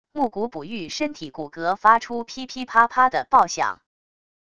木鼓卜域身体骨骼发出噼噼啪啪的爆响wav音频